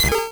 Cri de Roucool dans Pokémon Rouge et Bleu.